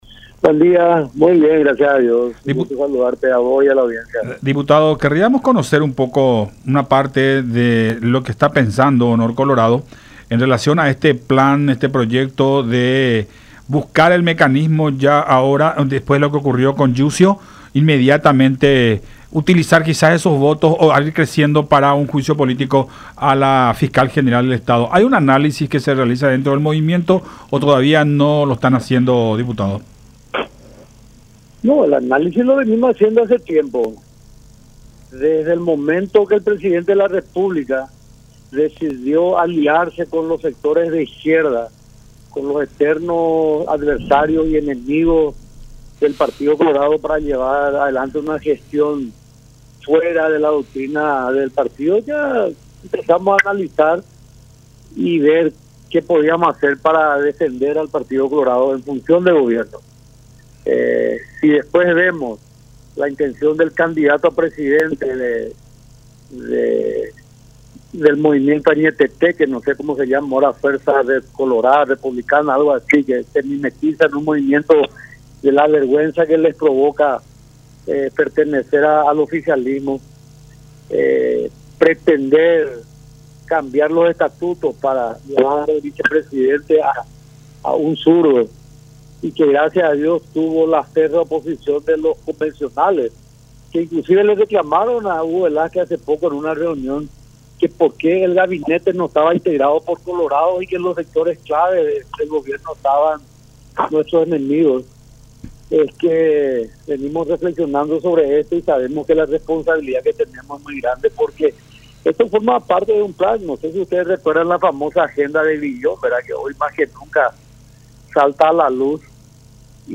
“Desde el momento que el presidente (Mario Abdo) tuvo alianza con sectores de izquierda, empezamos a analizar y ver qué hacer para defender al Partido Colorado en funciones de gobierno. De hecho que el objetivo es poner como Fiscal General del Estado a alguien del Frente Guasu”, comenzó diciendo Harms en conversación con Todas Las Voces por La Unión.